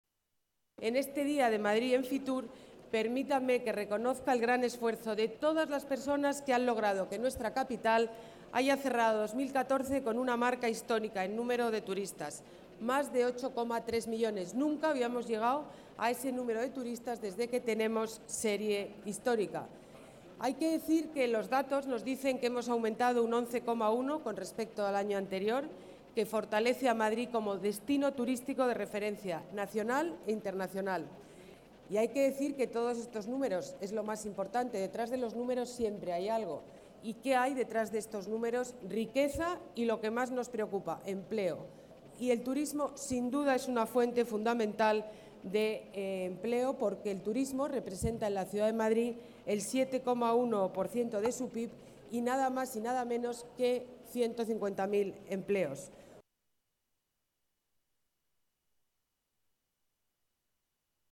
Nueva ventana:Declaraciones Ana Botella: Día Madrid Fitur